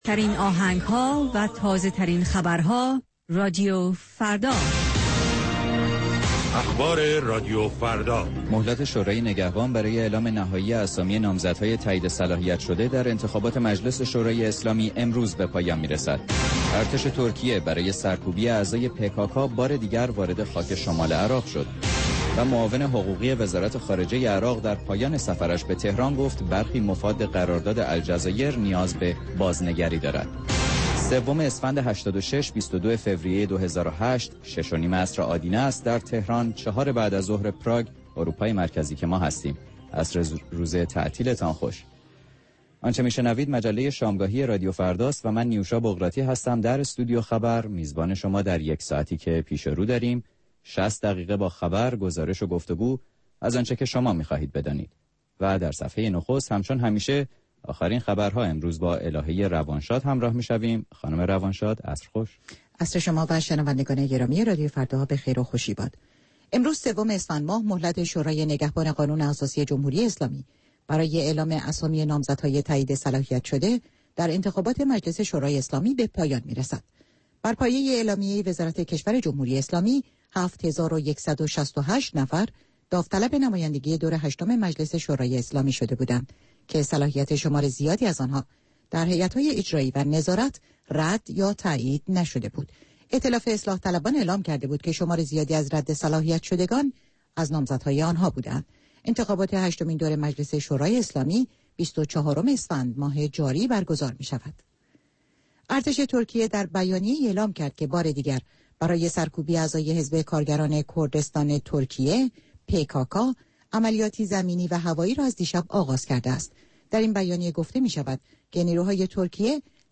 مجموعه ای متنوع از آنچه در طول روز در سراسر جهان اتفاق افناده است. در نیم ساعات مجله شامگاهی رادیو فردا، آخرین خبرها و تازه ترین گزارش های تهیه کنندگان این رادیو فردا پخش خواهند شد.